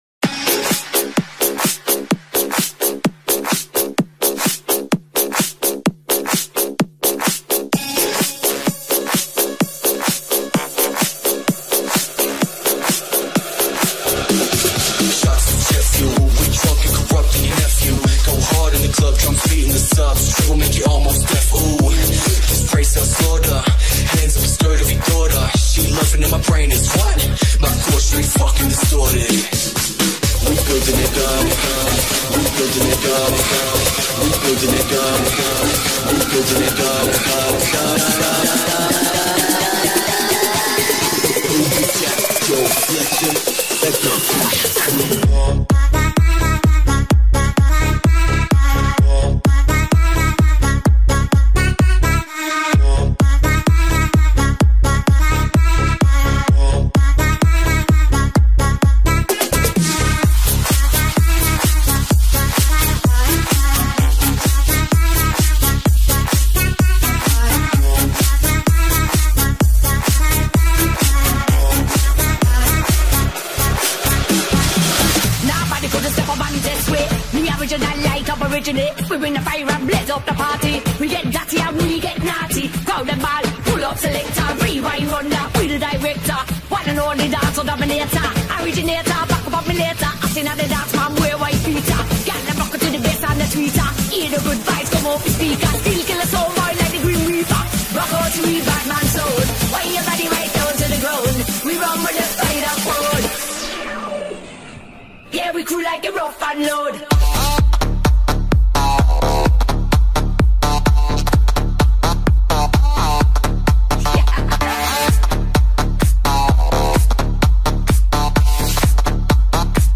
Electro_House_2016_I_Bounce_Party_Mix_Part_2.mp3